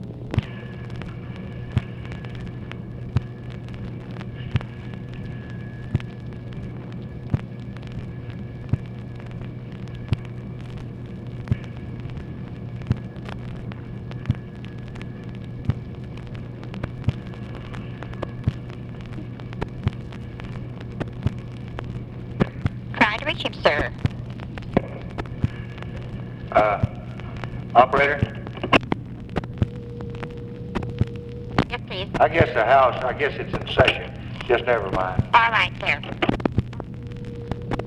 Conversation with TELEPHONE OPERATOR, August 4, 1964